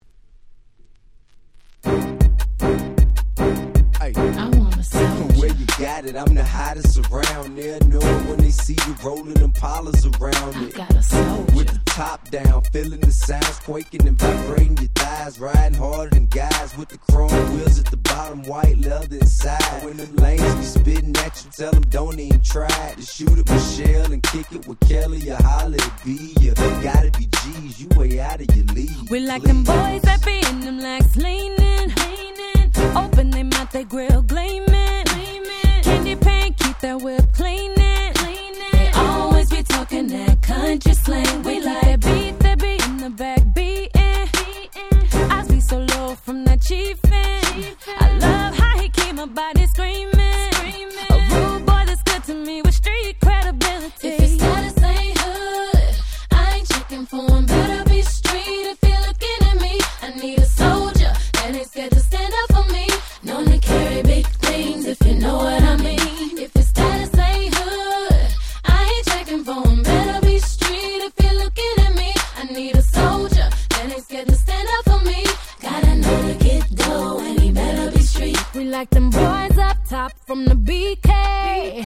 04' Super Hit R&B !!